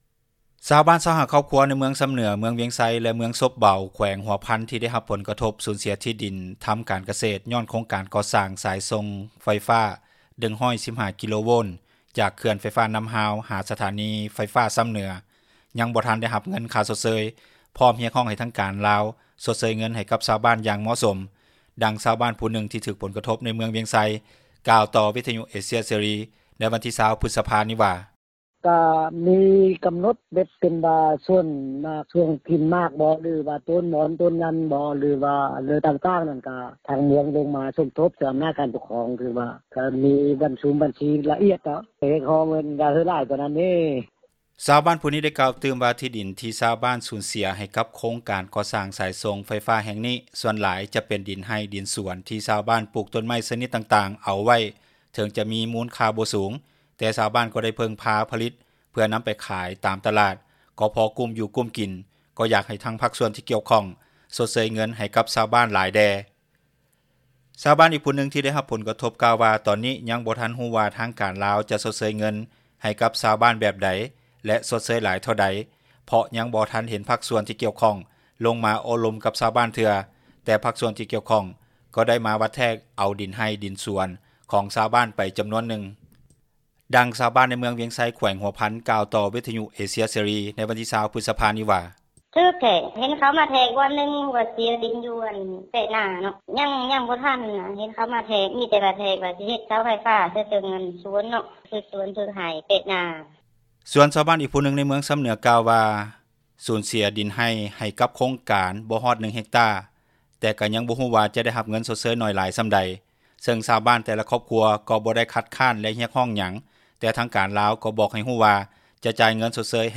ດັ່ງຊາວບ້ານຜູ້ນຶ່ງ ທີ່ຖືກຜົລກະທົບ ໃນເມືອງວຽງໄຊ ກ່າວຕໍ່ວິທຍຸເອເຊັຽເສຣີ ໃນວັນທີ 20 ພຶສພານີ້ວ່າ: